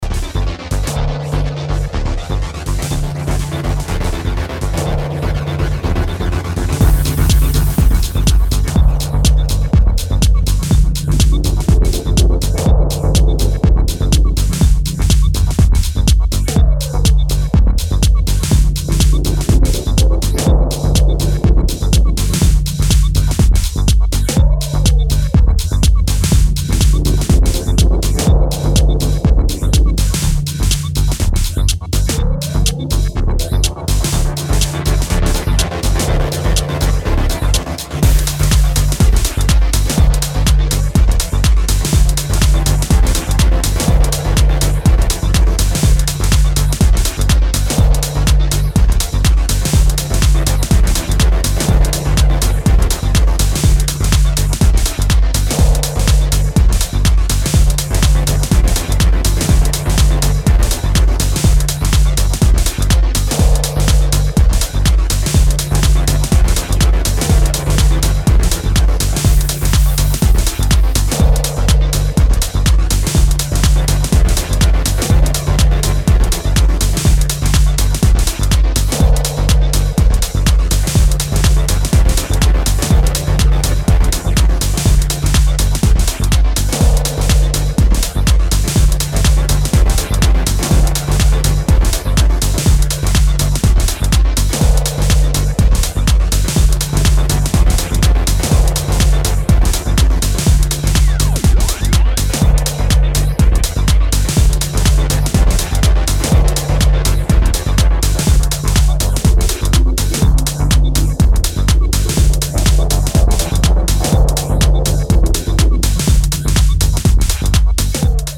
driving and groovy three track EP